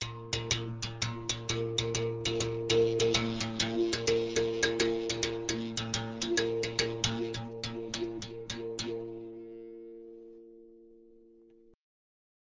On est donc face à un cordophone composé, comme beaucoup d’autres d’une corde, d’un manche supportant la corde et d’une caisse. cet arc est joué par pincement et par frappement.
Arc musical, enregistrement personnel